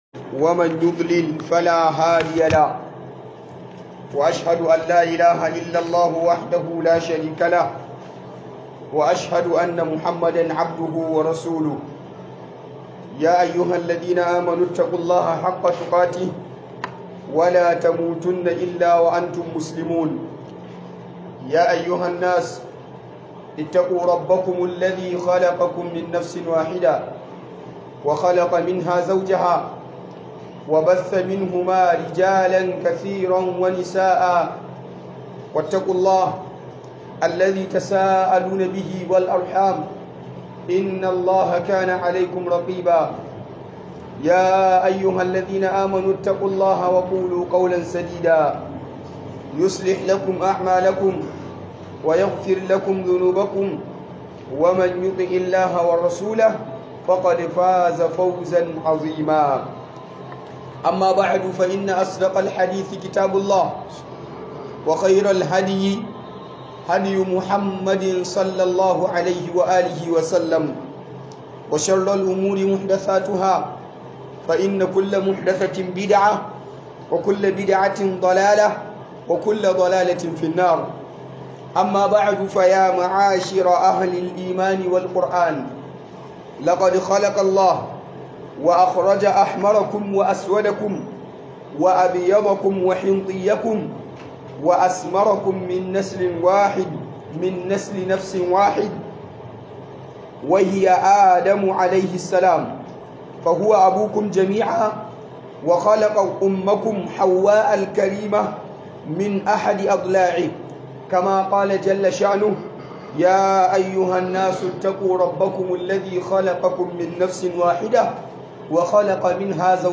Huduba Akan Qabilanci